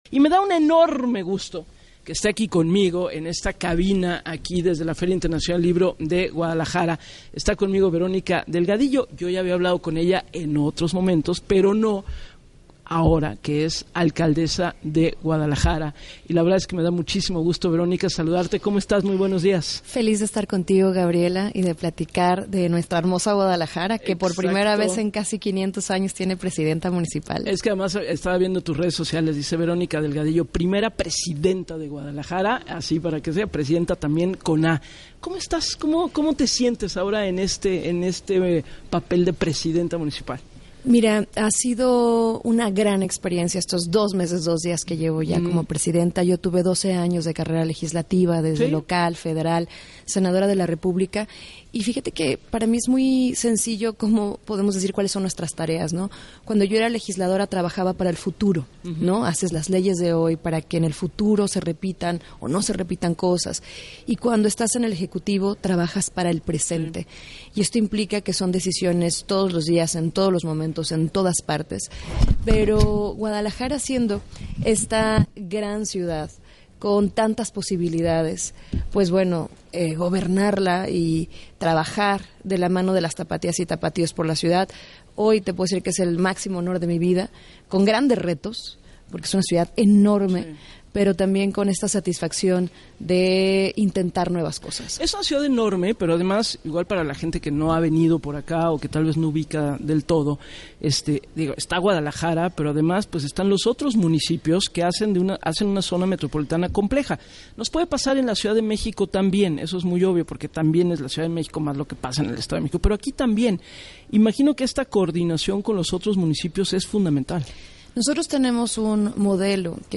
La alcaldesa de Guadalajara comparte su proyecto de “La Ciudad que te cuida” con total empatía a las familias buscadoras